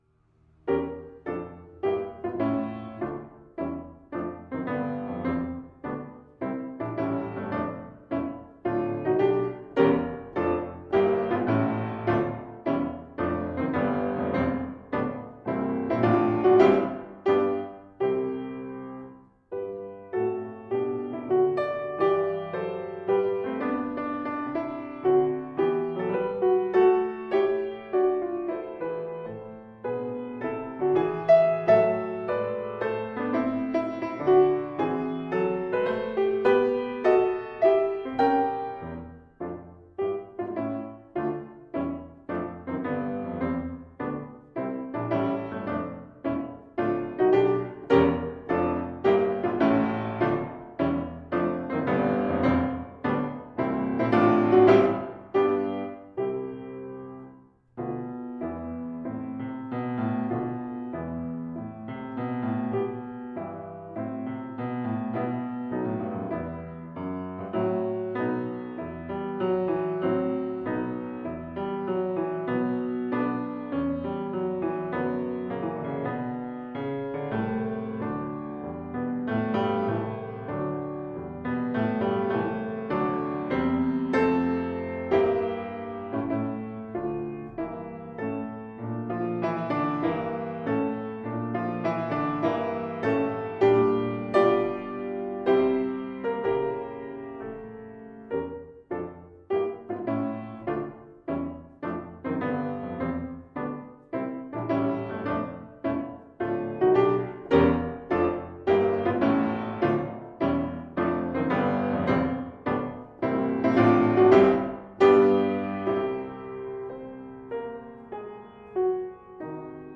Instrument: Piano
Style: Classical